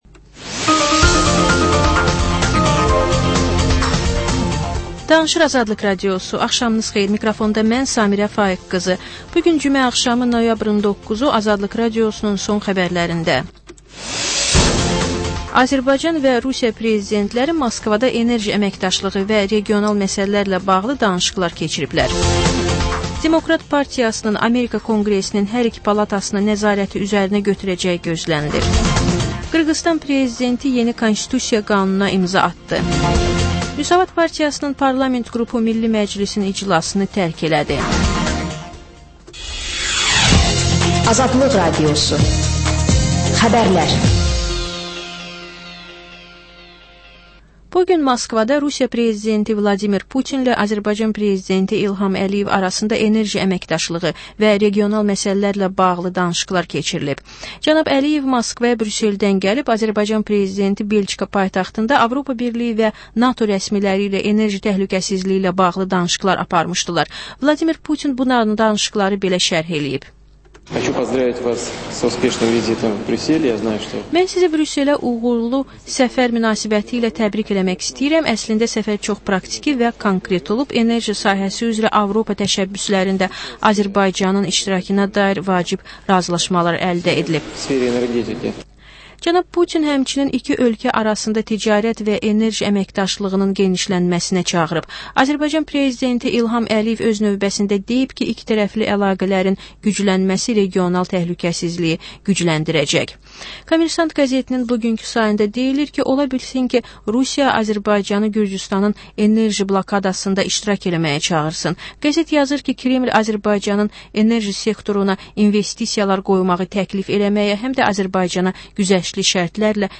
Xəbər, reportaj, müsahibə. Sonra: Və ən son: Qlobus: xaricdə yaşayan azərbaycanlılar.